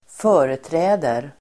Uttal: [²f'ö:reträ:der]